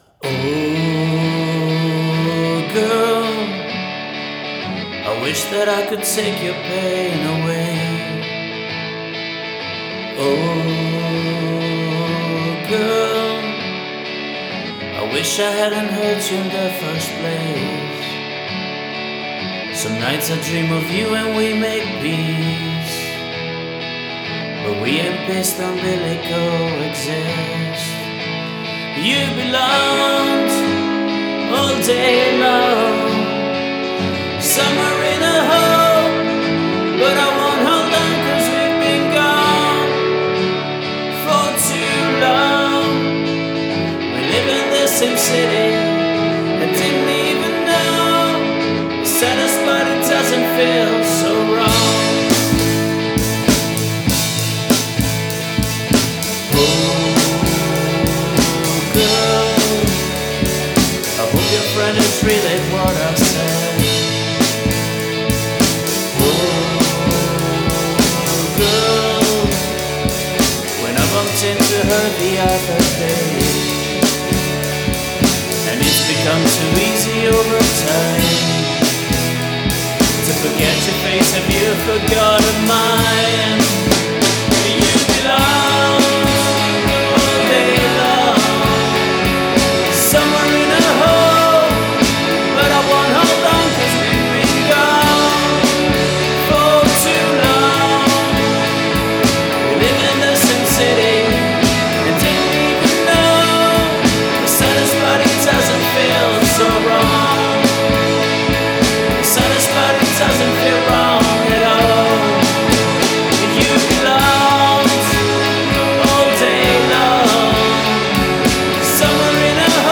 vocals, guitars, bass, drums, keyboards
backing vocals